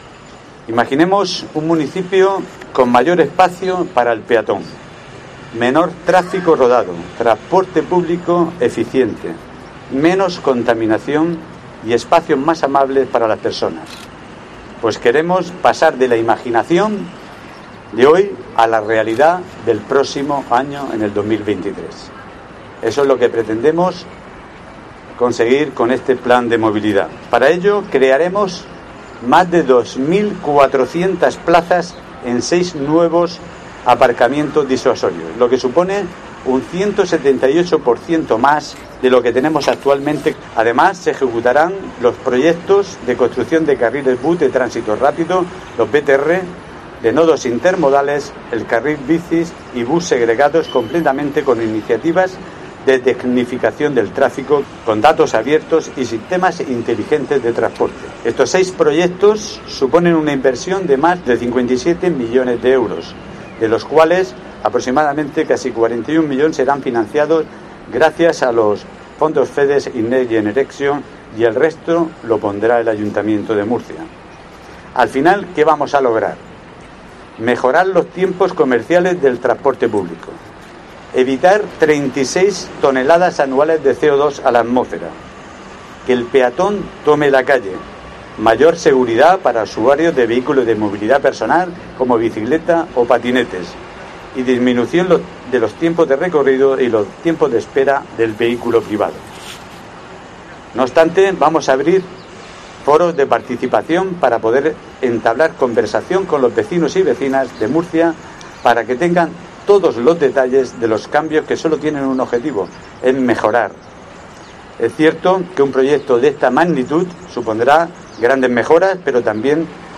José Antonio Serrano, alcalde de Murcia